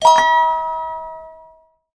星がきらめくイメージを表現した可愛らしい音色です。
その音色はまるで夜空に散りばめられた星々が輝くような響きを持ち、聴く者に幸せな気持ちを与えます。